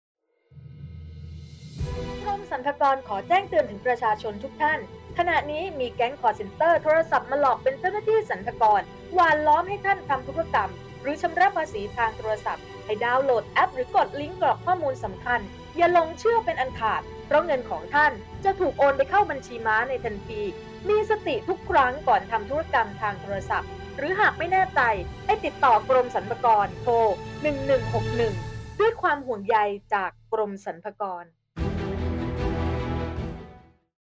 2.สปอตวิทยุ ความยาว  30 วินาที